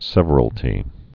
(sĕvər-əl-tē, sĕvrəl-)